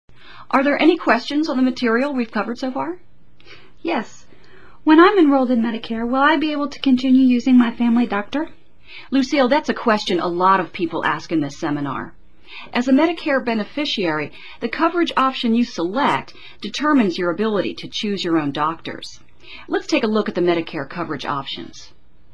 Select the Audio icon to hear the discussion between Lucille and the instructor or select the Text icon to read the script.